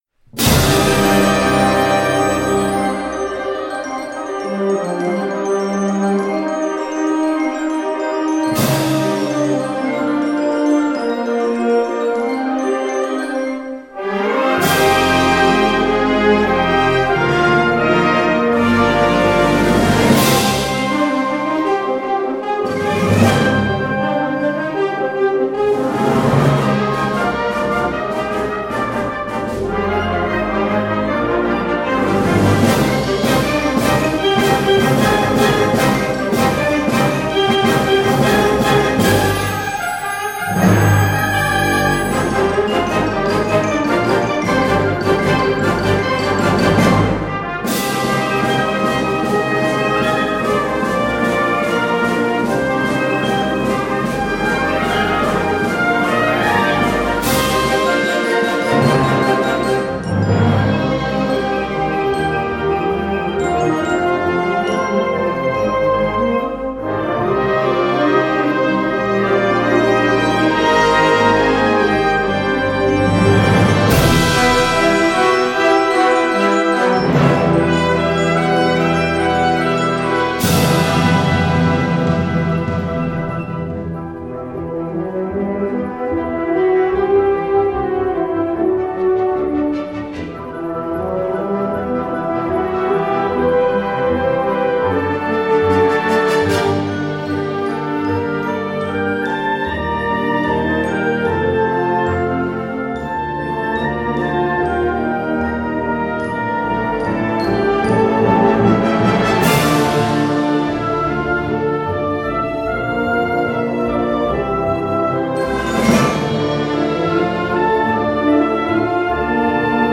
Categoria Concert/wind/brass band
Instrumentation Ha (orchestra di strumenti a faito)